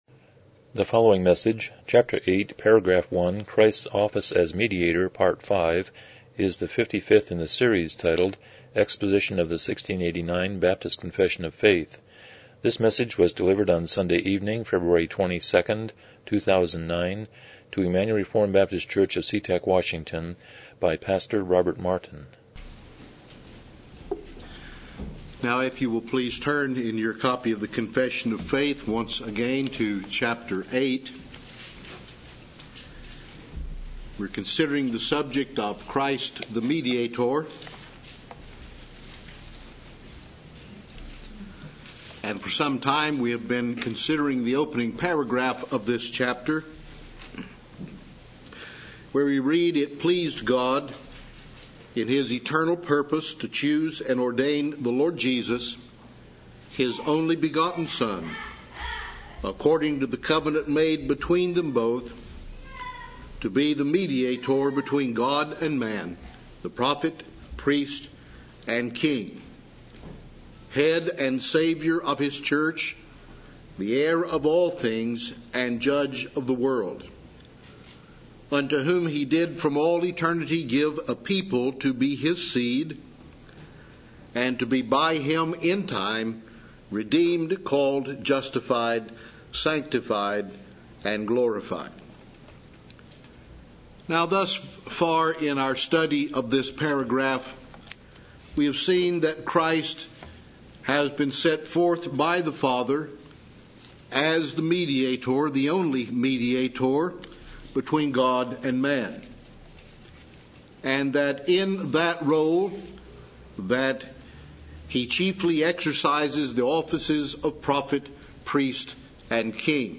1689 Confession of Faith Service Type: Evening Worship « How Do You Feel About God?